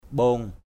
/ɓo:ŋ/